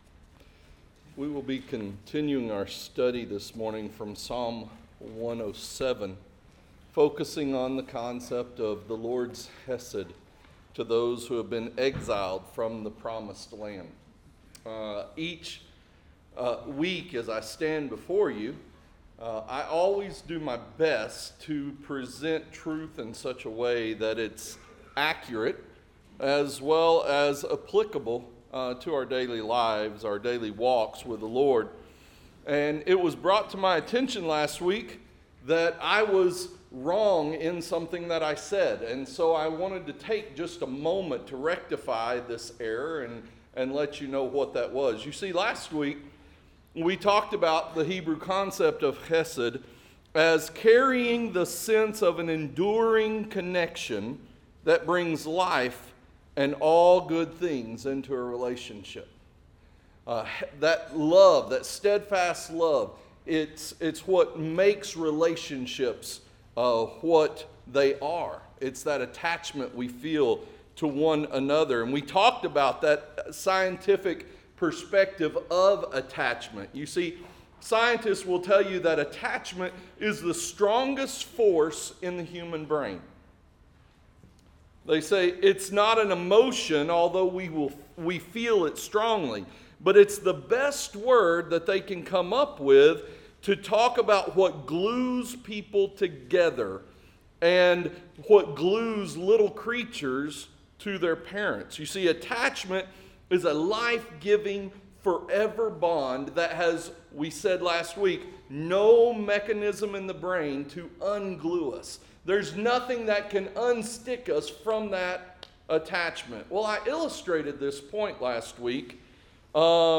Interactive Sermon Notes Recharge – Spring 2024 – Full PDF Download Recharge – Spring 2024 – Week Two Series: God's HESED